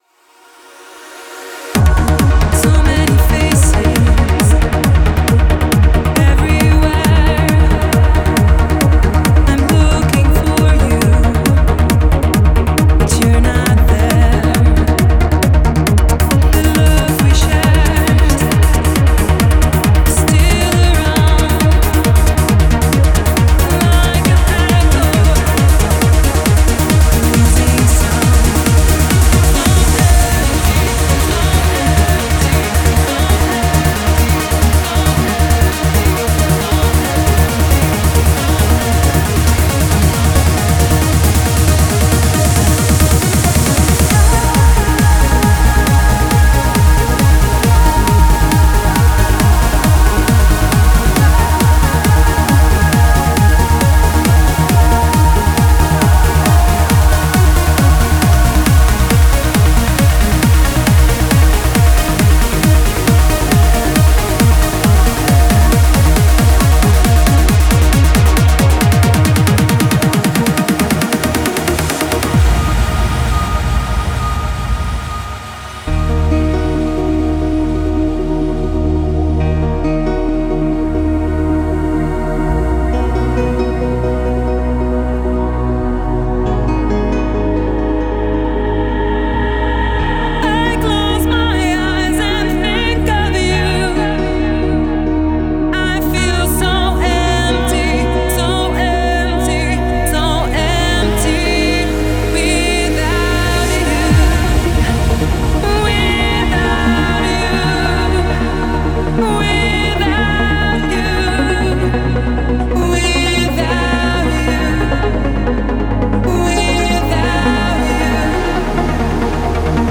Главная » Файлы » Trance